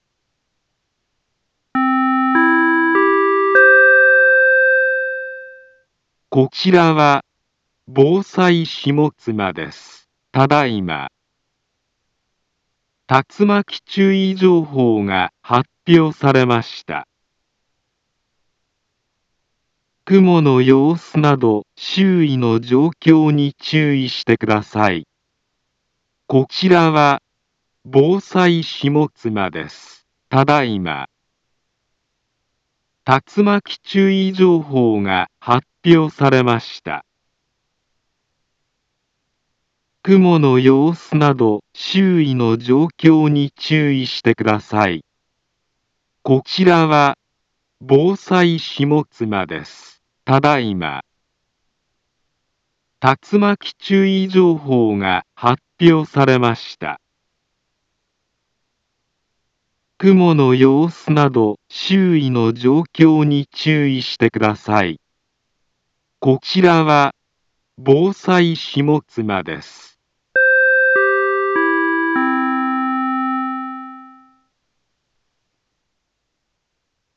Back Home Ｊアラート情報 音声放送 再生 災害情報 カテゴリ：J-ALERT 登録日時：2021-05-02 13:29:49 インフォメーション：茨城県南部は、竜巻などの激しい突風が発生しやすい気象状況になっています。